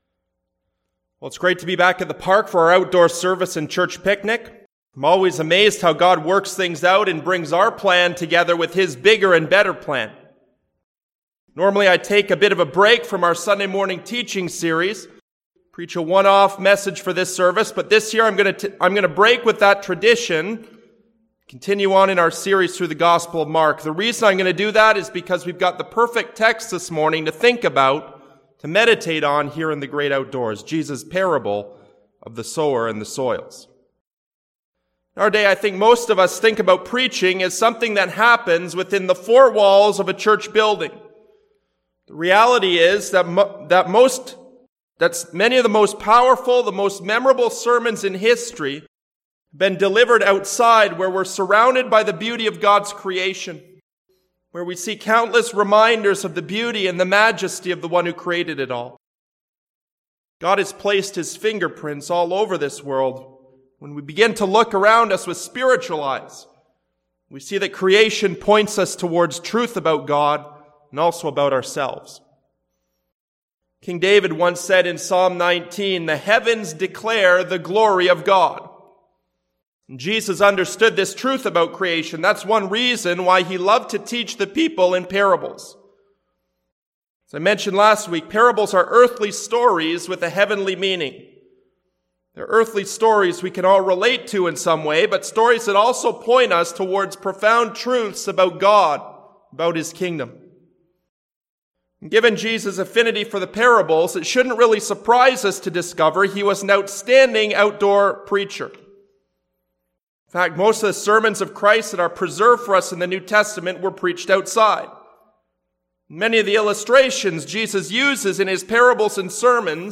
preach